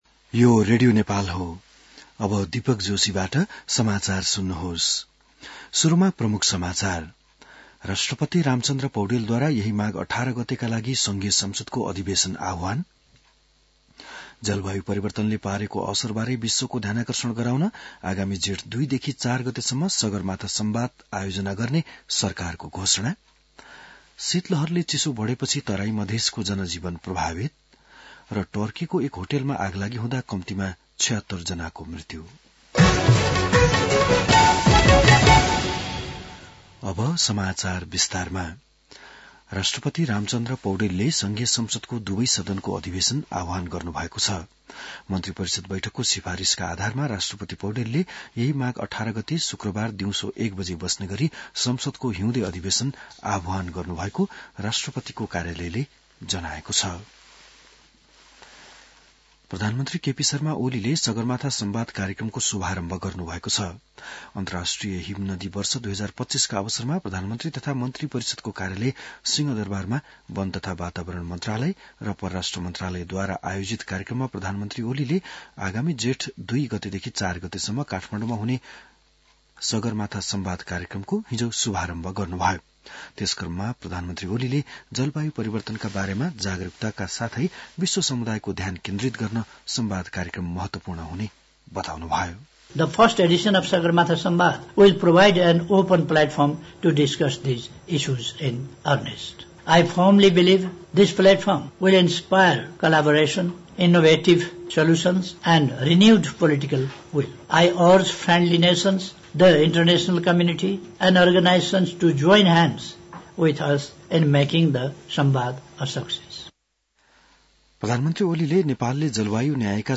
बिहान ९ बजेको नेपाली समाचार : १० माघ , २०८१